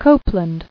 [Cop·land]